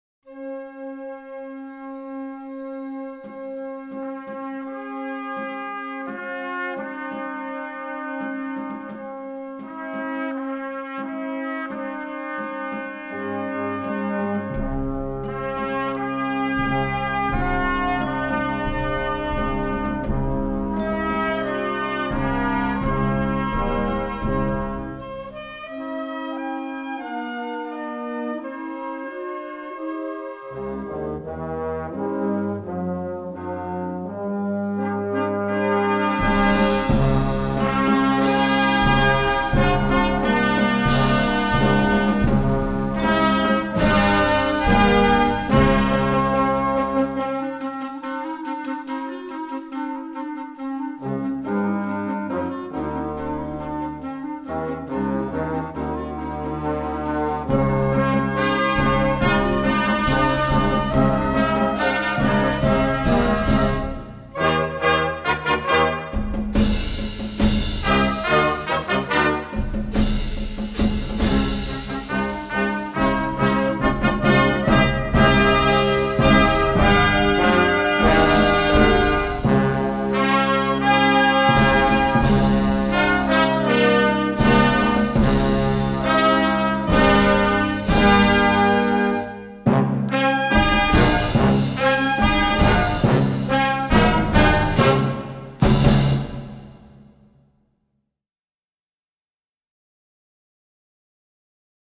Gattung: Konzertwerk für Jugendblasorchester
Besetzung: Blasorchester
Leichte Musik, die fortgeschritten klingt!